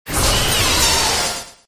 pokerogue / public / audio / cry / 884-gigantamax.ogg